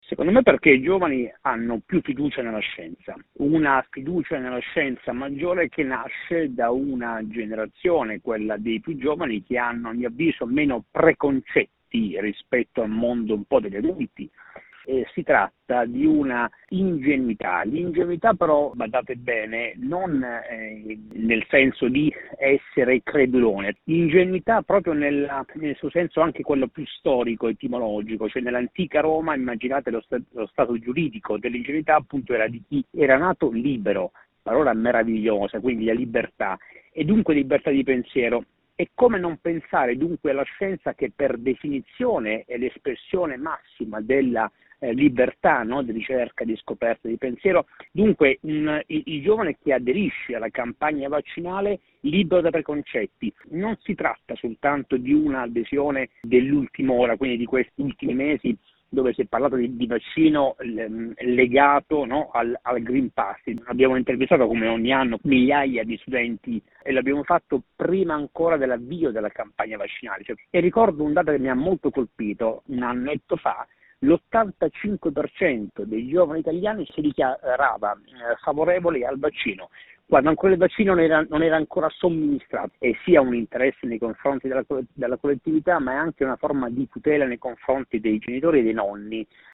Lo abbiamo chiesto al sociologo